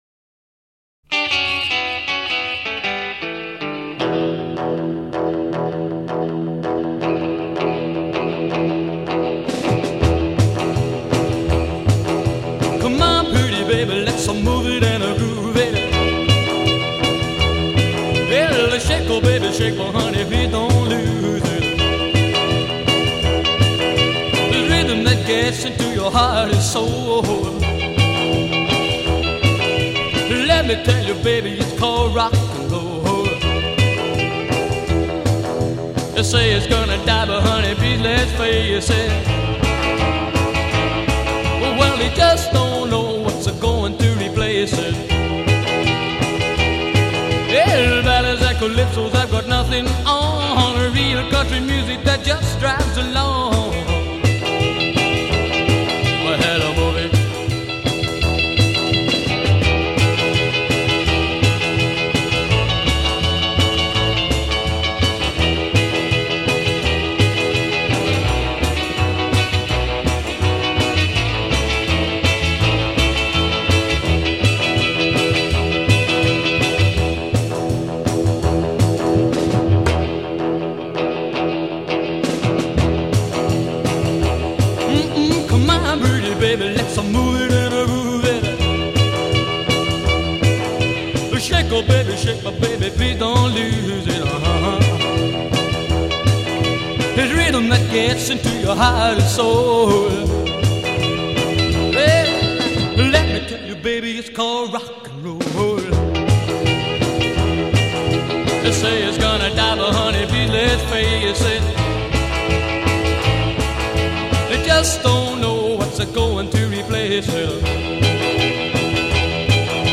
Recorded at EMI Recording Studios, Studio Two, 24 July 1958.
A" coda : 8+ repeat hook and fade b'